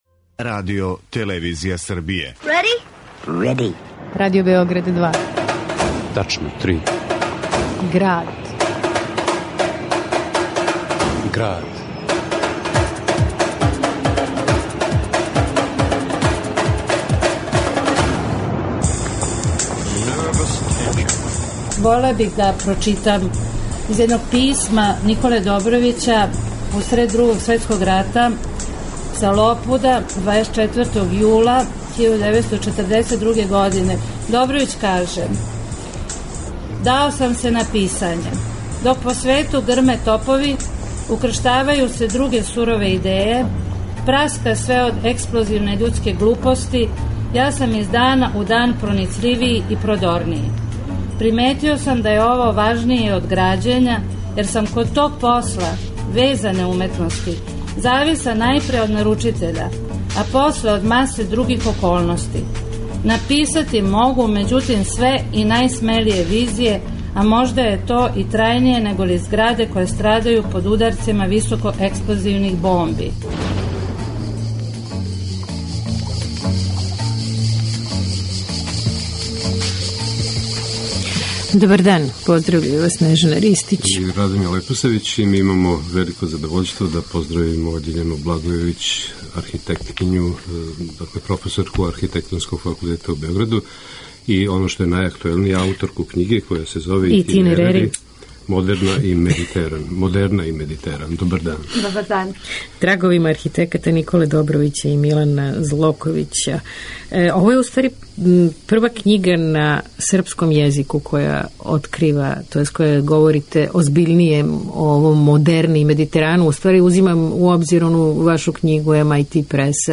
уз документарне снимке